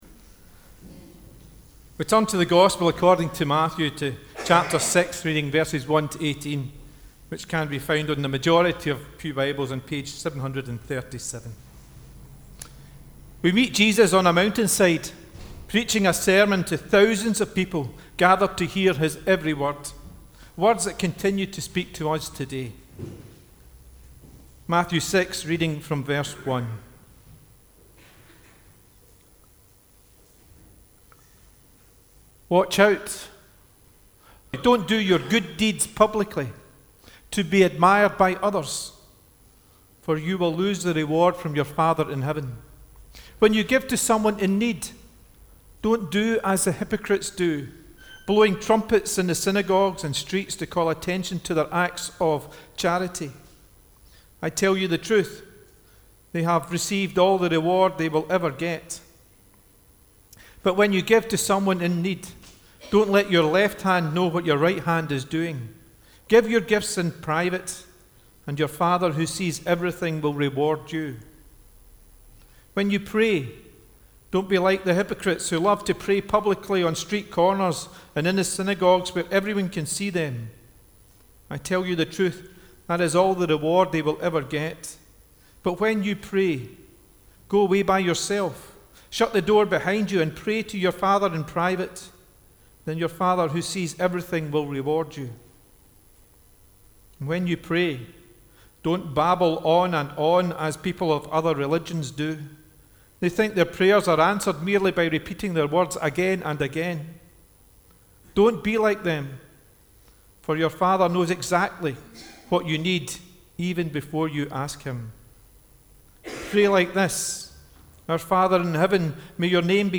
The Scripture Reading prior to the Sermon is Matthew 6: 1-18